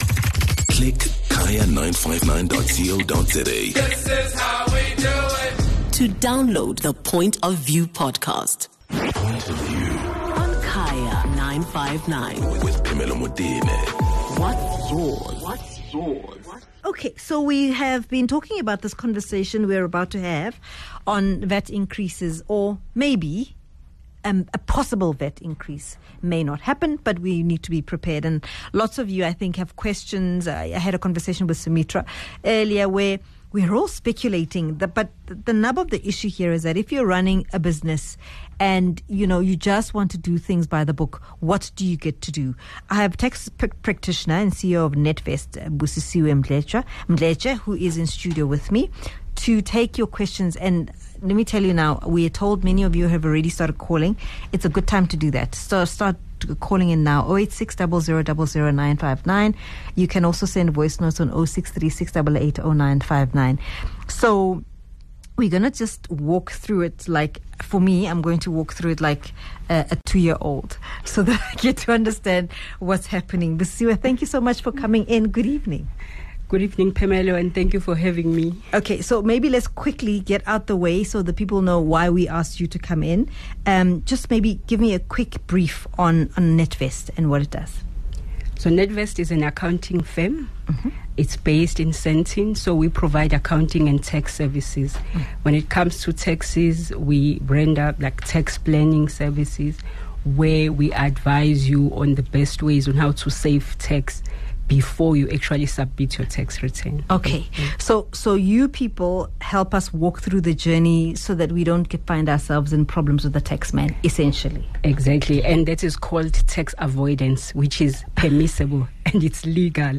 22 Apr DISCUSSION: Unpacking VAT